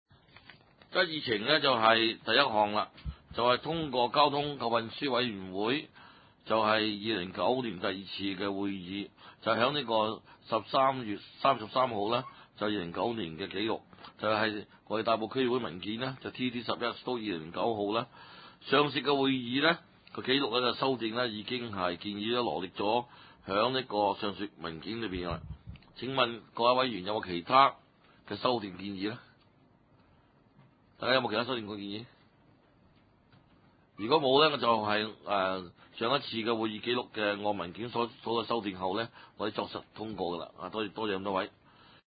地點：大埔區議會秘書處會議室